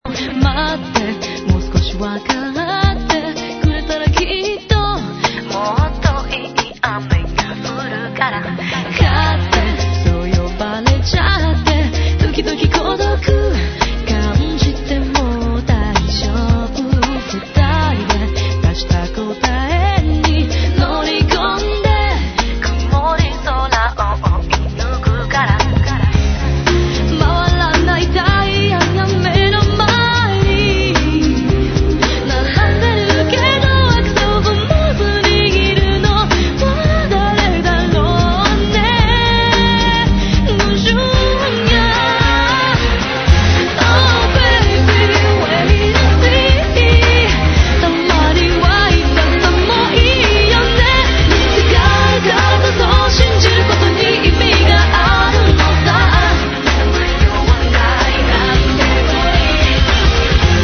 Genre : Pop/R'n'B/Funk